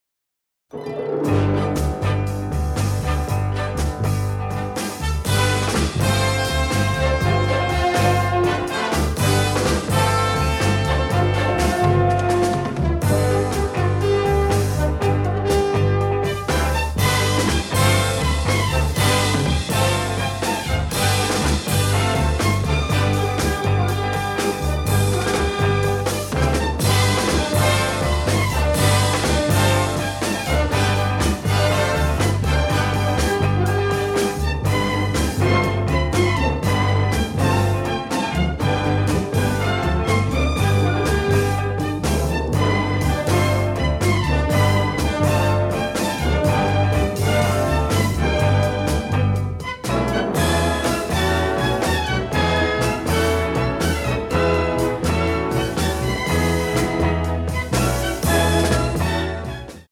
great exotica and pop tunes